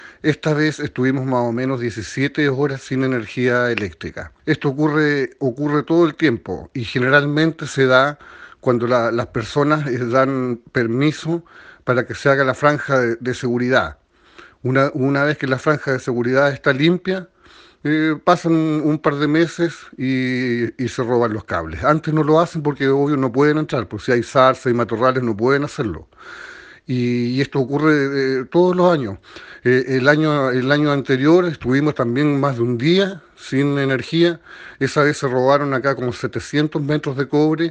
Lo anterior afectó el suministro de energía eléctrica por más de 17 horas, donde decenas de usuarios se vieron afectados como fue el caso de un usuario que nos relató lo vivido.